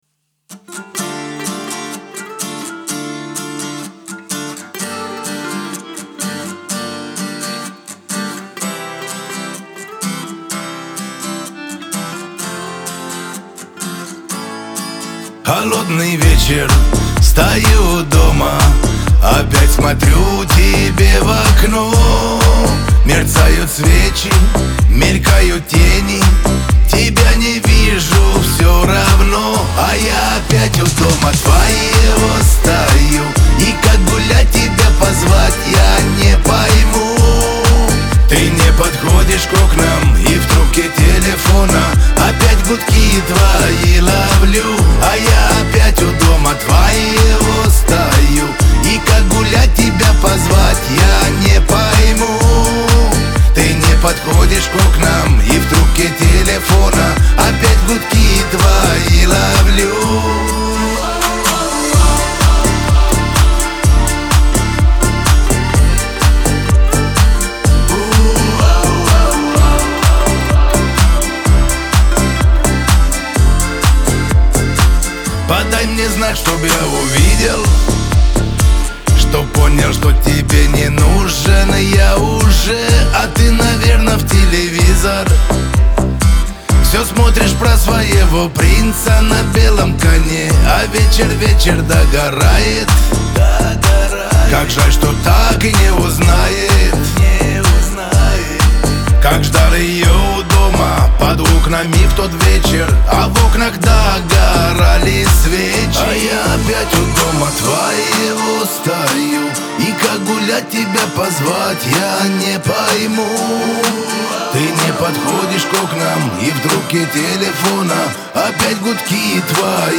Шансон , Лирика